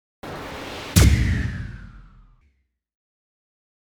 Tiếng Bom nổ trong phim Khoa Học Viễn tưởng
Thể loại: Đánh nhau, vũ khí
Description: Là hiệu ứng tiếng nổ của quả bom nhằm tiêu diệt những thứ hiện diện trước mặt, là âm thanh chiến tranh nổ ra, tiếng bom nổ như phá hủy và thiêu rụi những sự vật hiện diện trước tầm nhìn của nó, là âm thanh thường sử dụng trong các bộ phim khoa học viễn tưởng.
Tieng-bom-no-trong-phim-khoa-hoc-vien-tuong-www_tiengdong_com.mp3